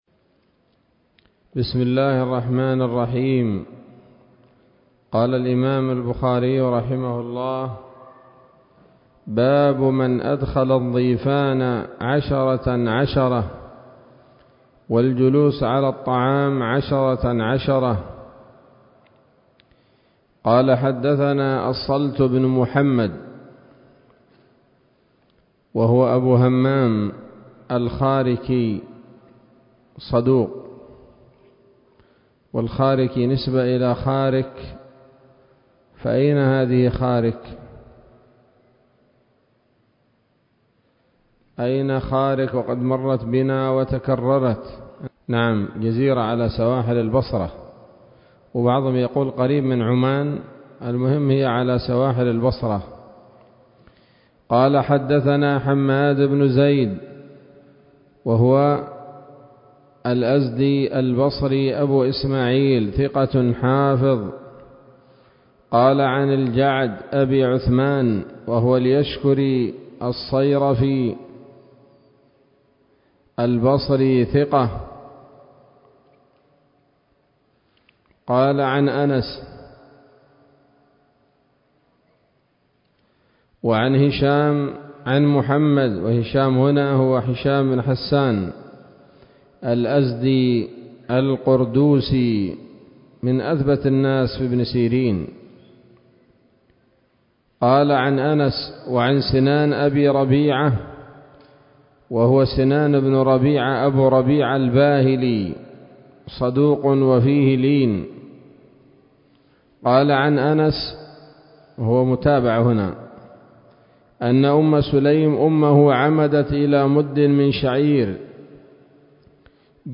الدرس السادس والعشرون من كتاب الأطعمة من صحيح الإمام البخاري